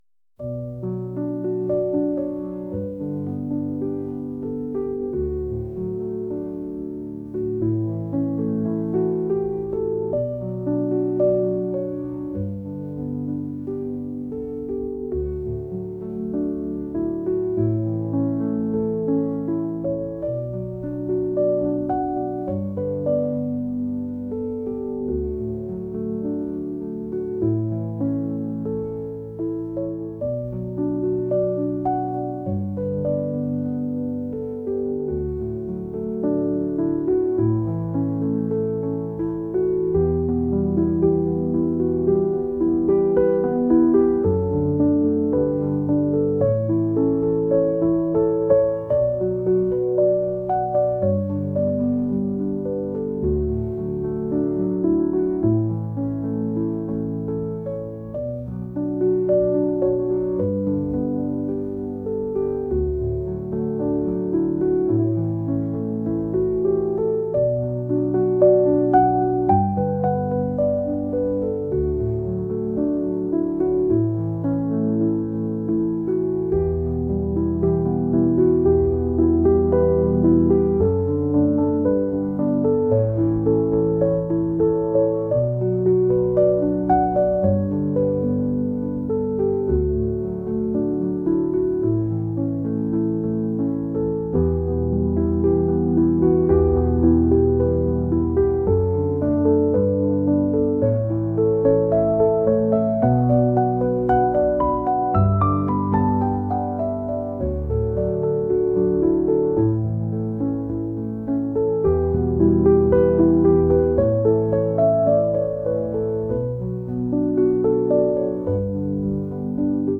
classical | ambient | cinematic